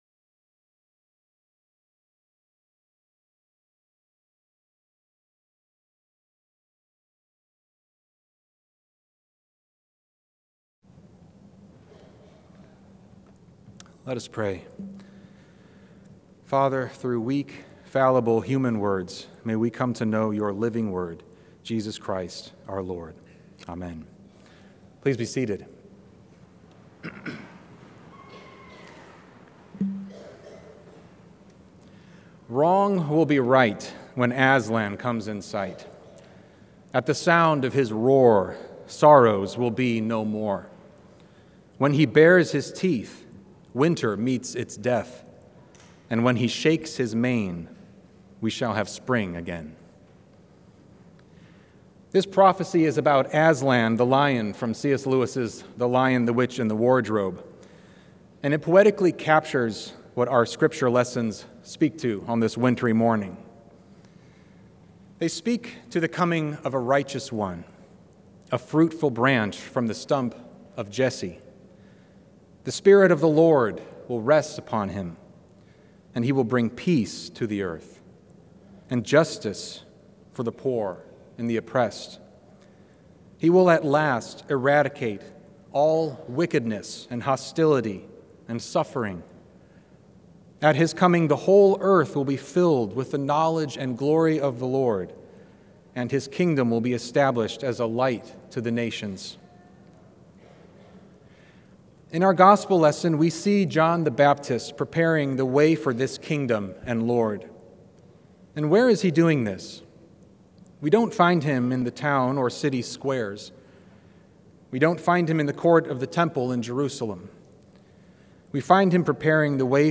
Sermons | Church of the Good Shepherd